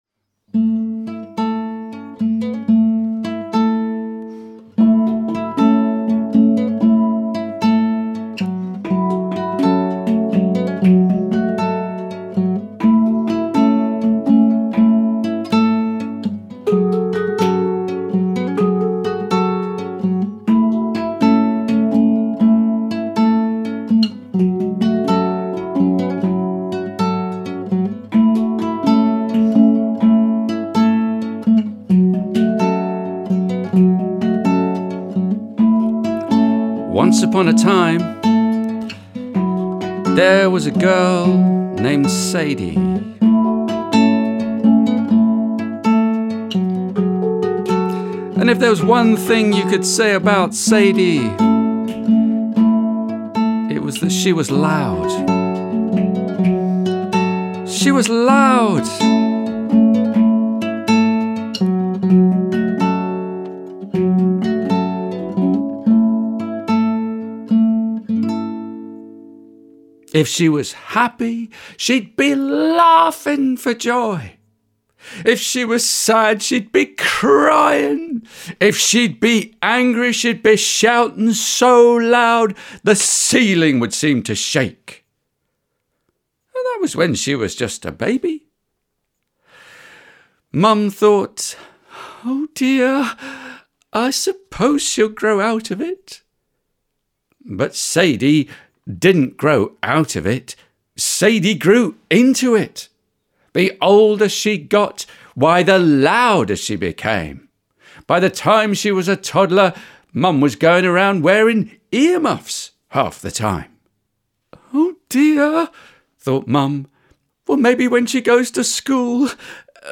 Here’s a story for children all about self-expression.